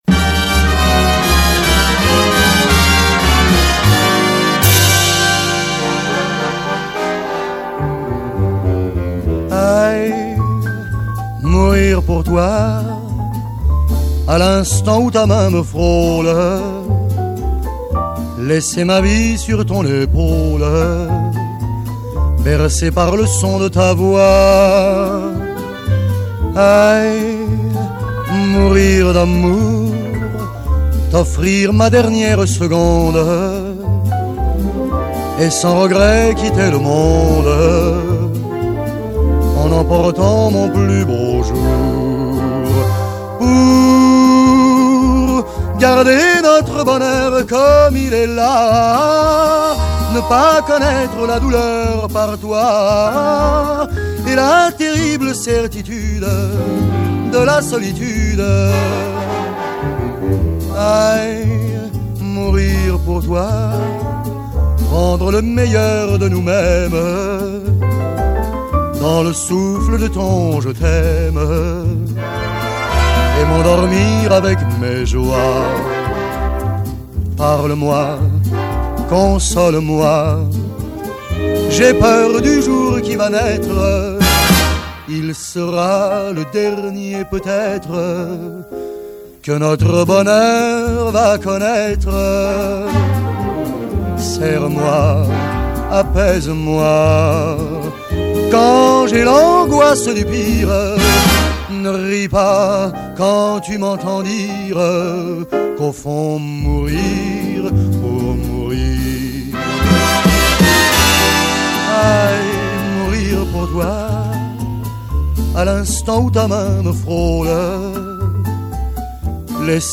Chanson, Pop